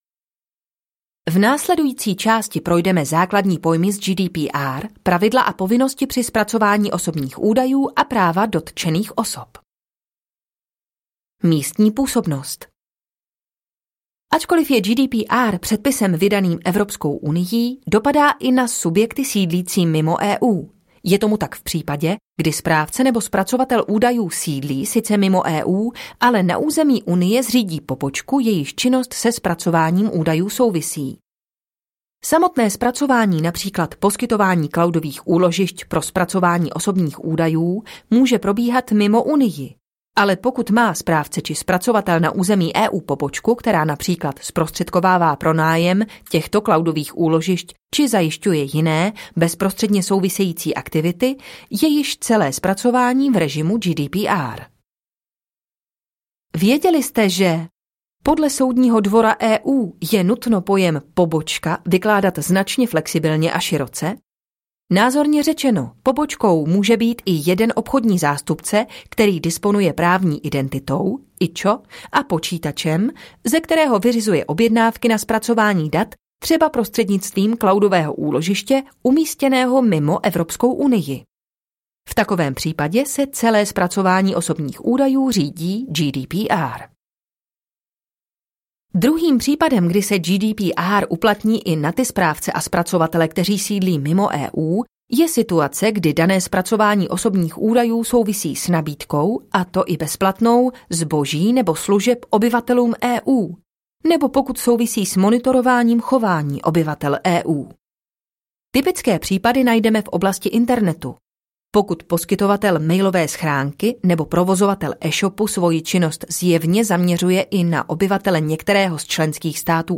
Příručka Pověřence pro ochranu osobních údajů audiokniha
Ukázka z knihy